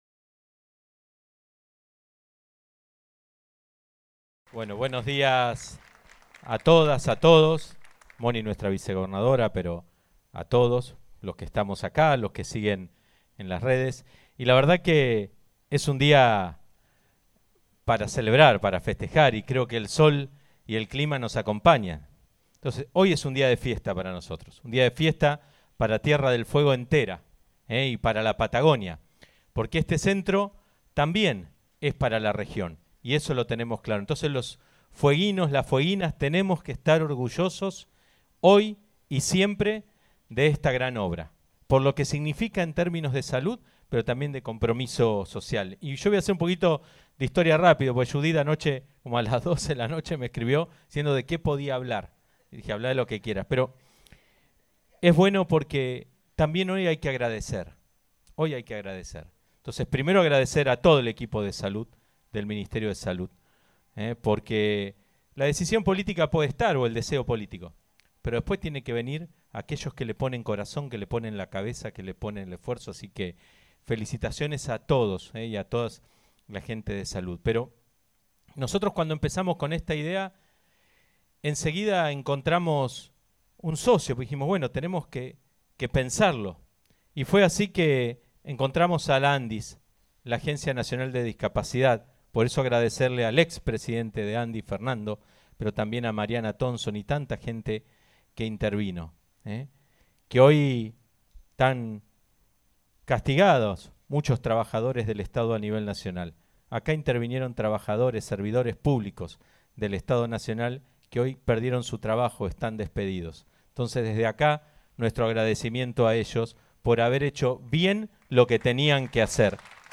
Estuvieron presentes la Vicegobernadora Mónica Urquiza, funcionarios y funcionarias del Ejecutivo Provincial, autoridades del orden nacional, provincial y local, autoridades judiciales, veteranos de guerra, representantes de diversas organizaciones y asociaciones de la sociedad civil, equipos de salud, pacientes y familiares.
Discurso-Melella-Inauguracion-CPR.mp3